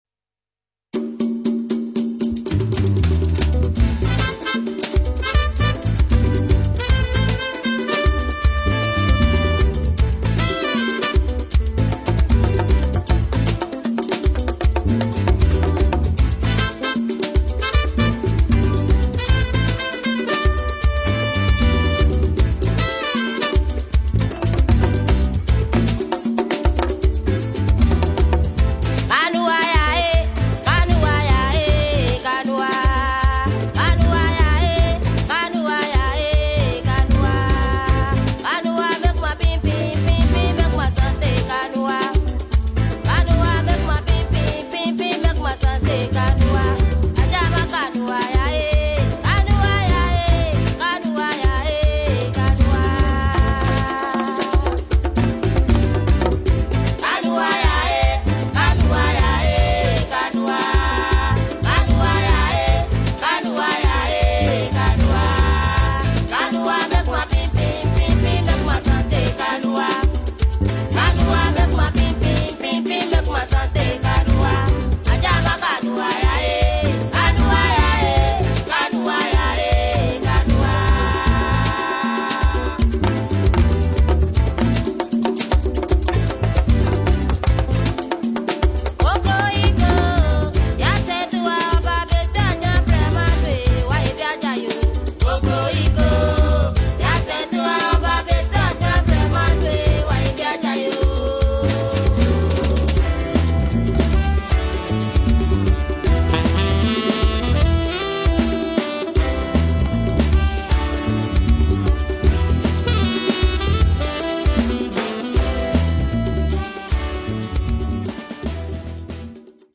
Weltmusik aus Ghana.